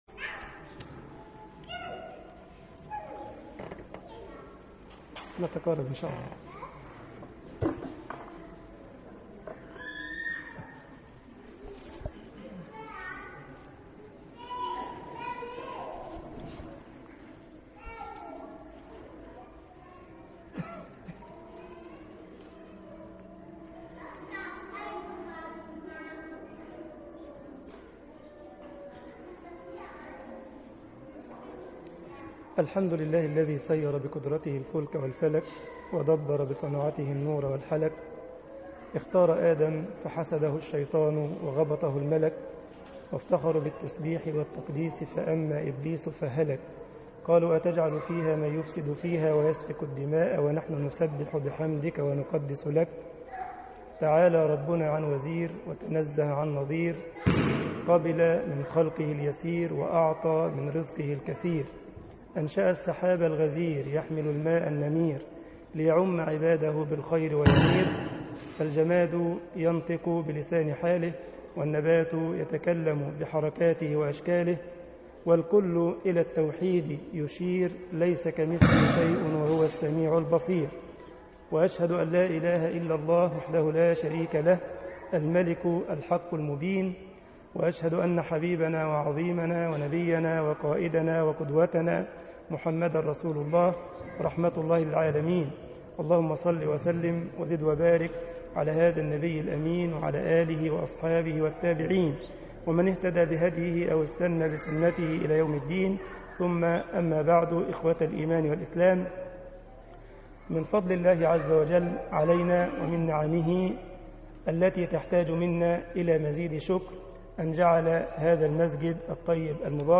مسجد الجمعية الاسلامية بالسارلند محاضرة